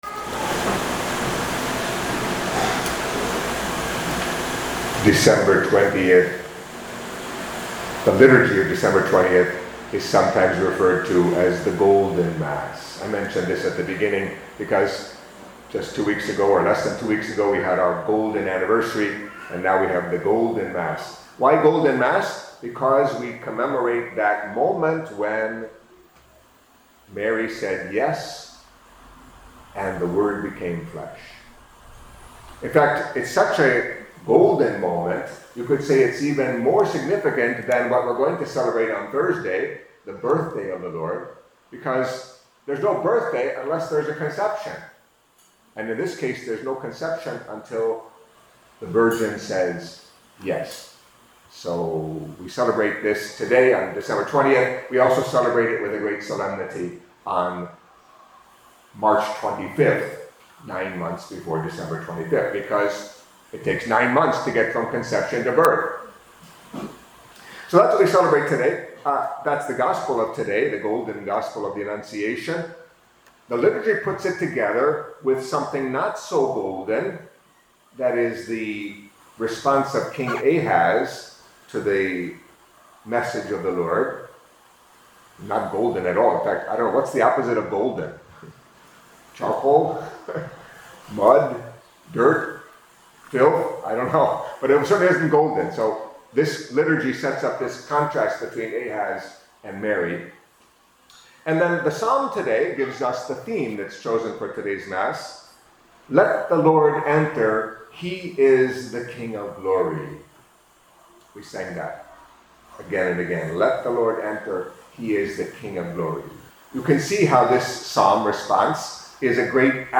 Catholic Mass homily for Saturday of the Third Week of Advent